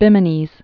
(bĭmə-nēz)